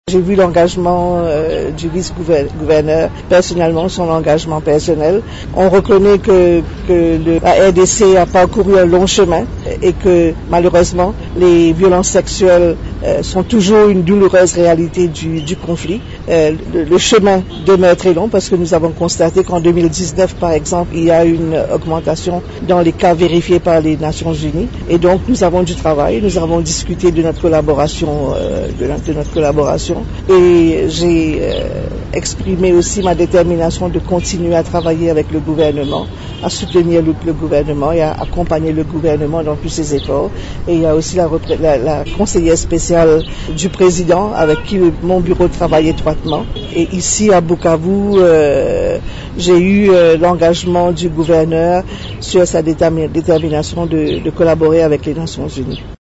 Cette déclaration a été faite ce samedi 30 novembre à Bukavu au Sud-Kivu à l’issue de sa rencontre avec les autorités provinciales.